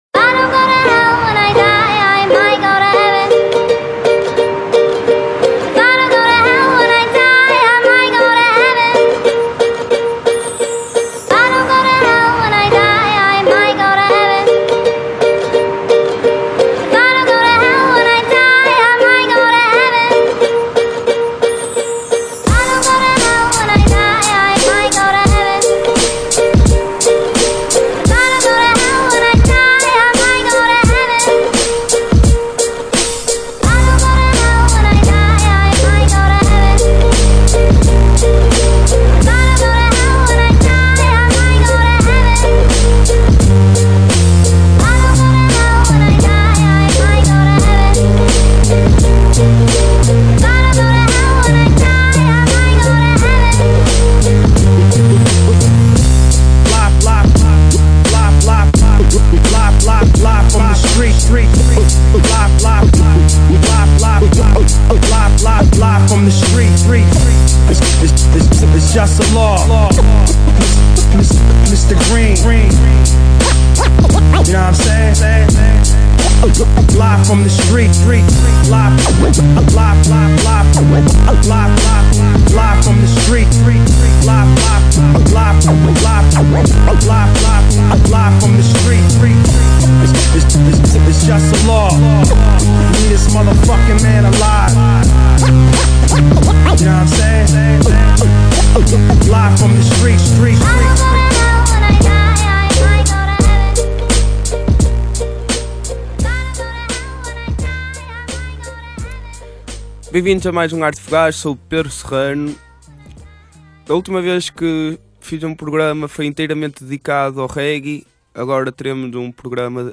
Só bom Hip Hop